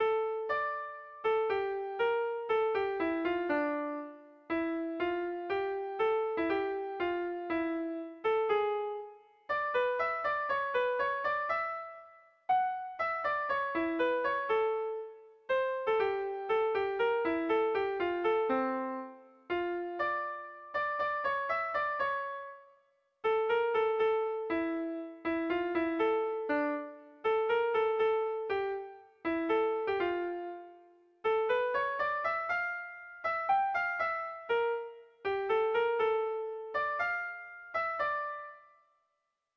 Zortziko handia (hg) / Lau puntuko handia (ip)
ABDE